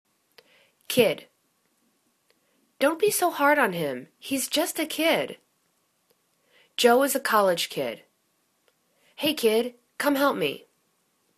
kid     /kid/    n